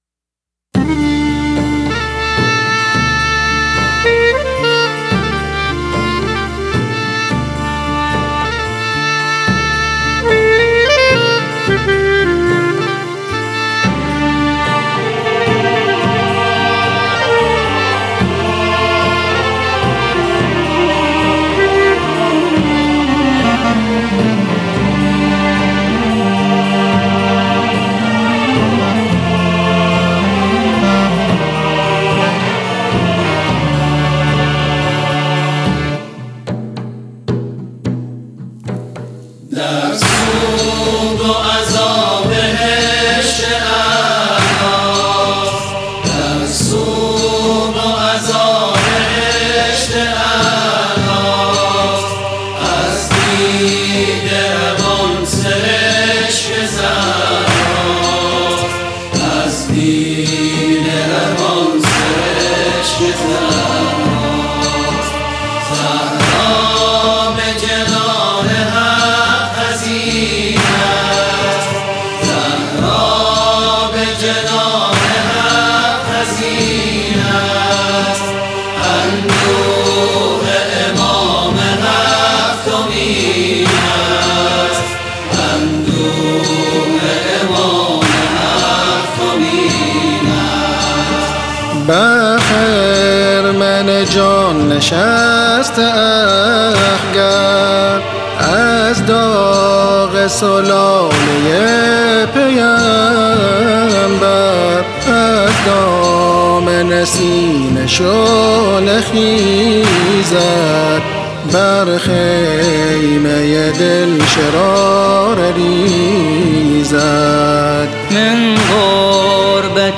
سوگنامه
قطعه موسیقایی و آیینی
شعر آئینی و مذهبی